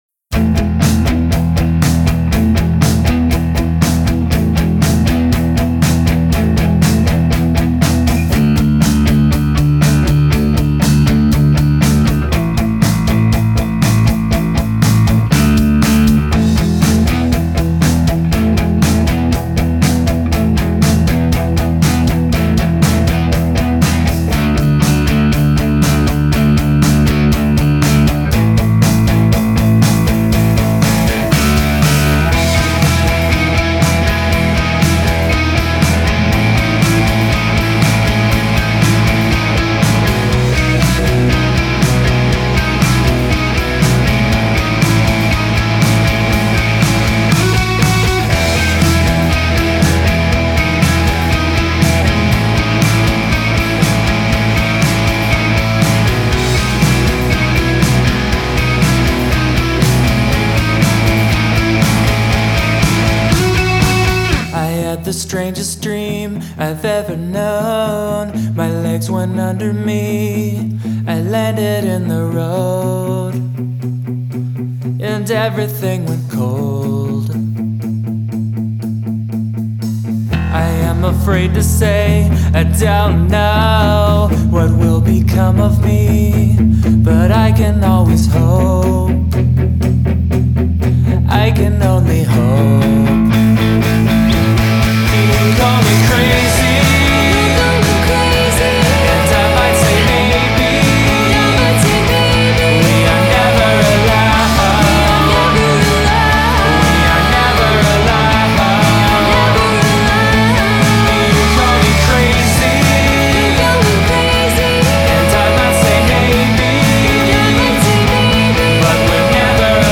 Hope you have fun headbanging to this one.